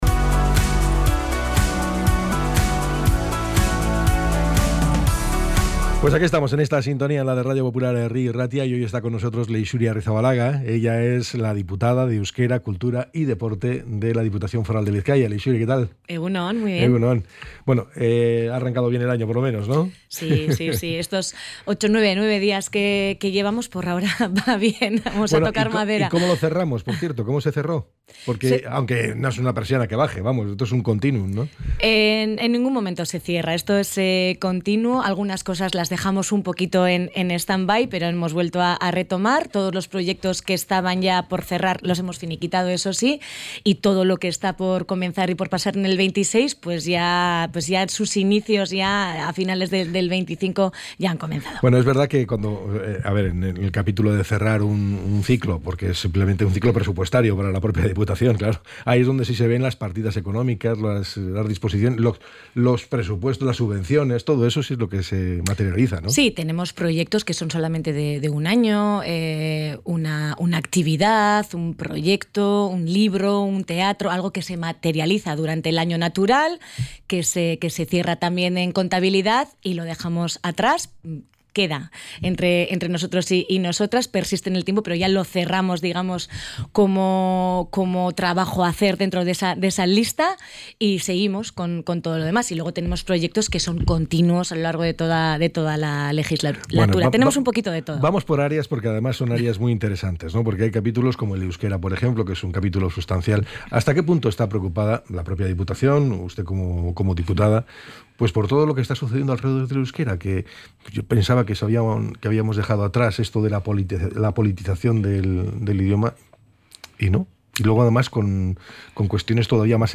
ENTREV.-LEIXURI-AGIRREZABALAGA.mp3